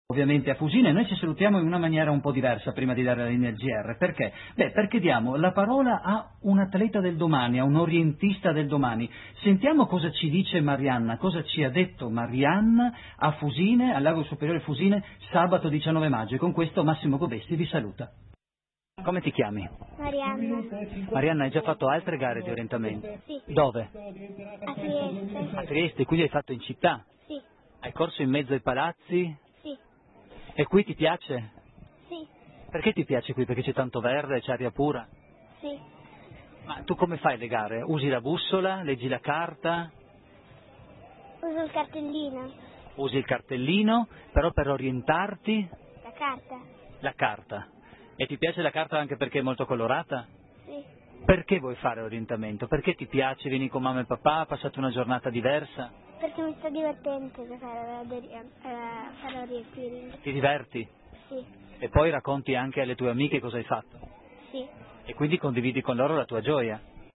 Intervista radiofonica